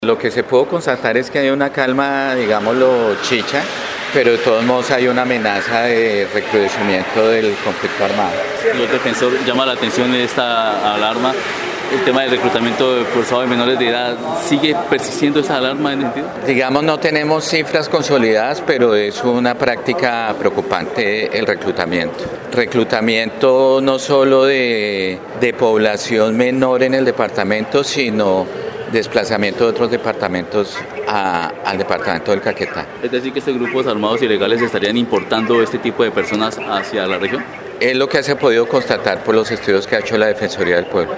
Roberto Molina Palacios, Vice Defensor del Pueblo, quien estuvo en esta parte del país en una sesión de la comisión de derechos humanos de la cámara de representantes, explicó que el tema preocupa como quiera que estos grupos armados ilegales, no solo estarían reclutando en territorio, también en otros departamentos.